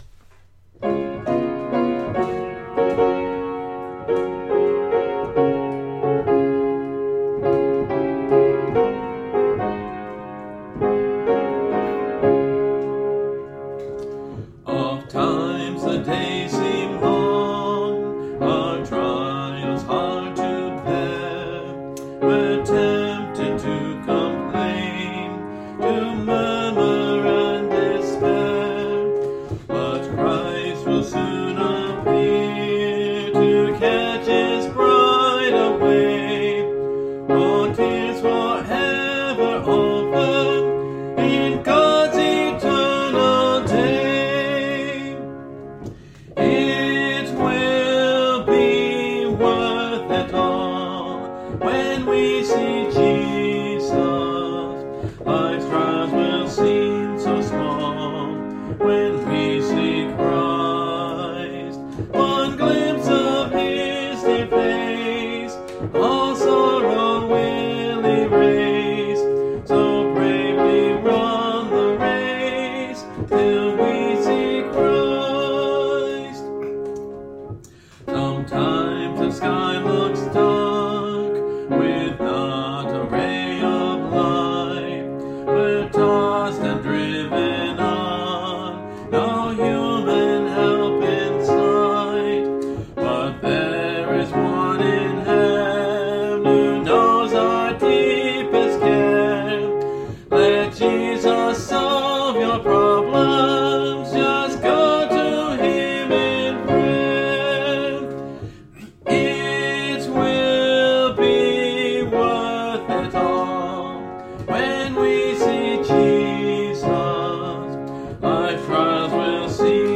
Part of a series singing through the hymnbook I grew up with